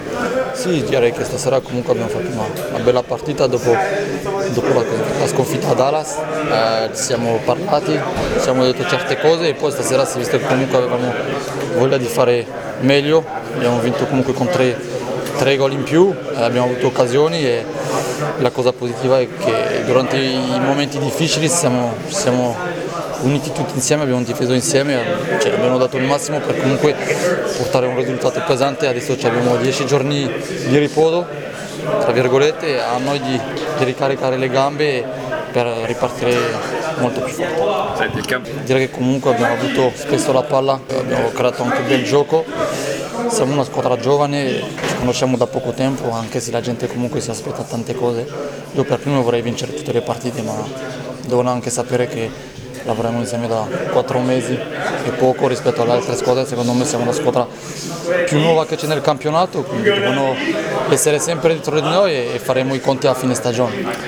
Interviste post partita: